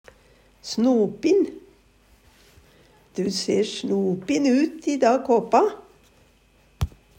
snopin - Numedalsmål (en-US)